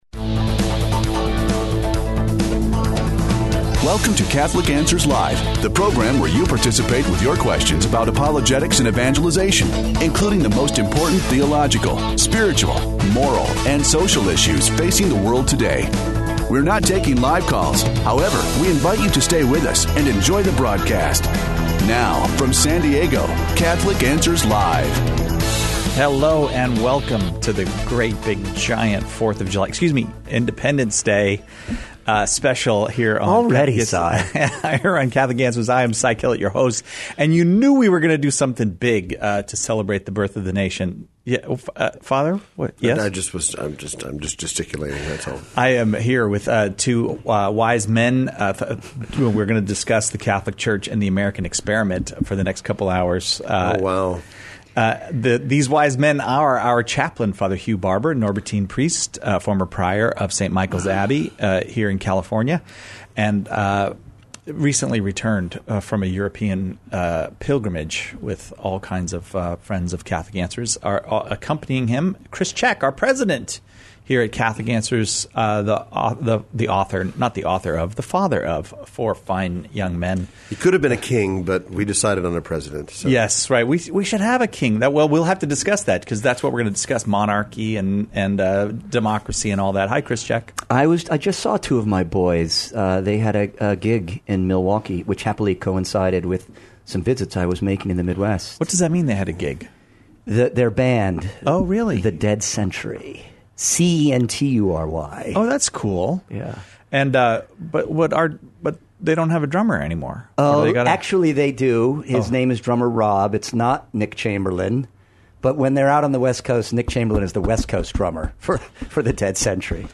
an extended conversation